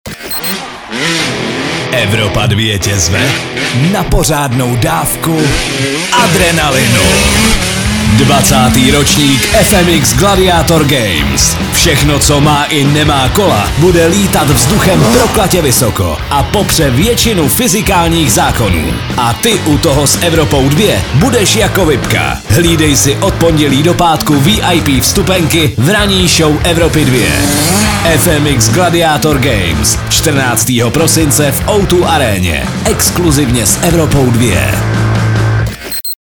liner_fmx_gladiator_games_soutez_2.mp3